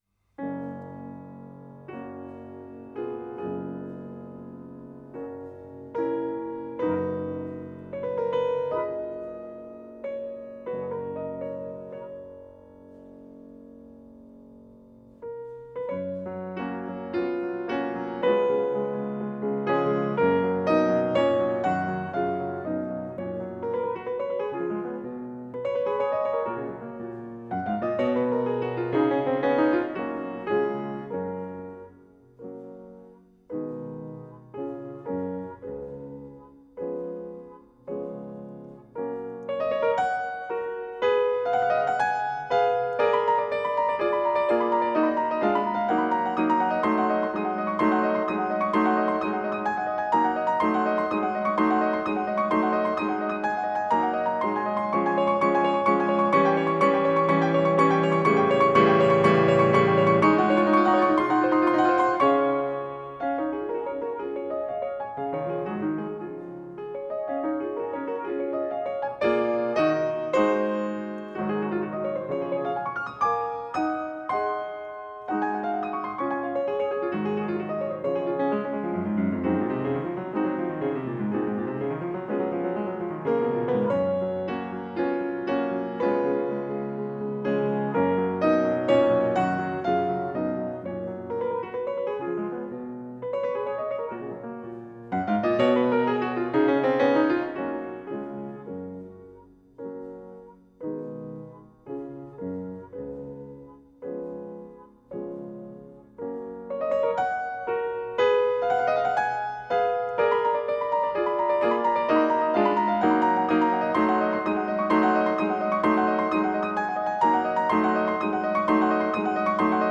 Classical Beethoven, Ludwig van Piano Sonata No.24 in F sharp major, Op.78 (A Threse) Piano version
Free Sheet music for Piano
8n_beethoven_sonata_24.mp3